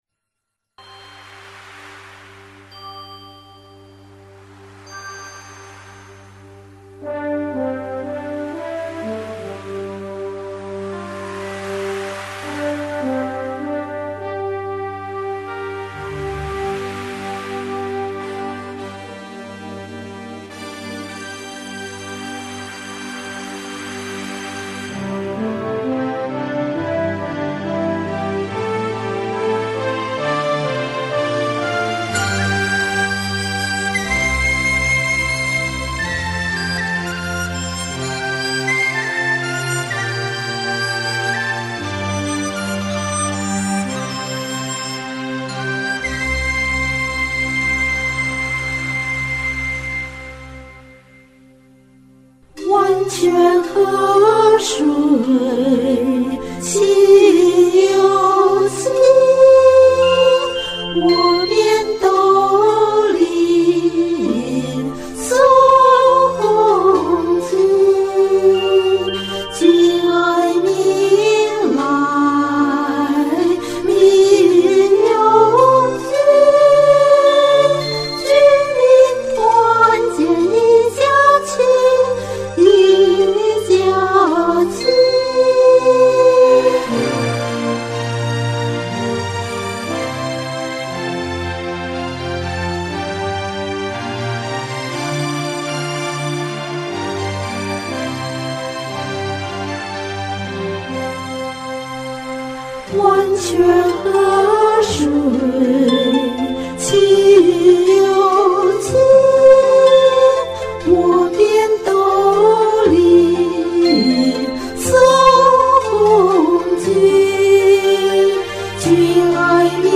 好像伴奏速度有点慢，也没找到其它版本的，就先用它吧！